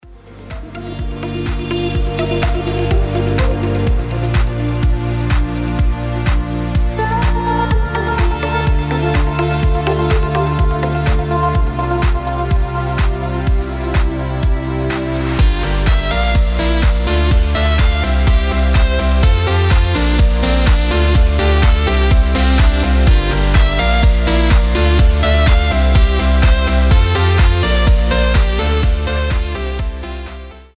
• Качество: 320, Stereo
атмосферные
Electronic
красивая мелодия
нарастающие
progressive house
Красивый прогрессив на будильник